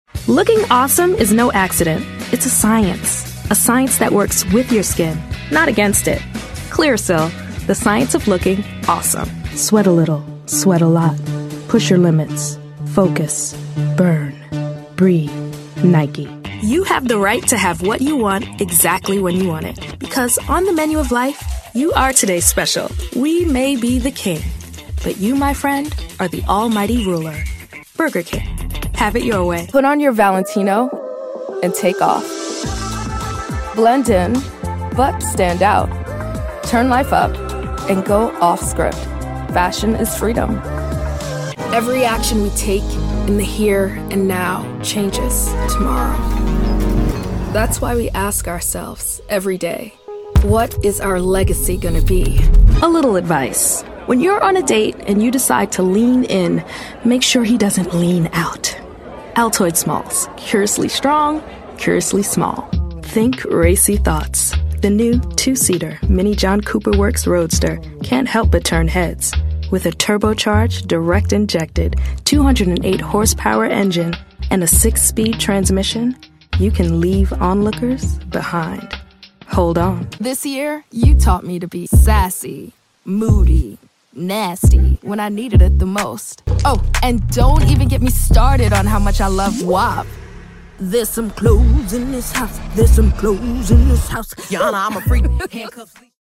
animation 🎬